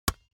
جلوه های صوتی
دانلود صدای تصادف 47 از ساعد نیوز با لینک مستقیم و کیفیت بالا